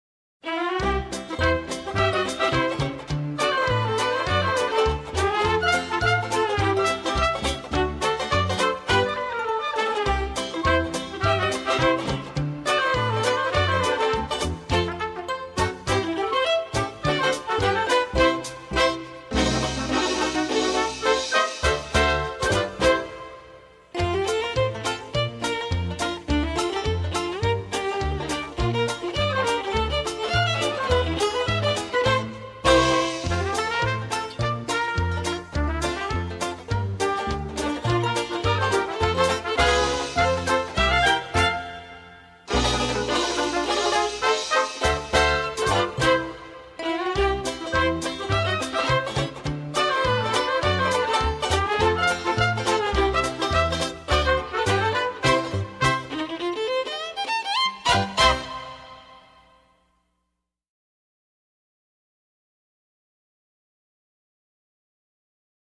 背景音乐为轻松欢快的游戏配乐
该BGM音质清晰、流畅，源文件无声音水印干扰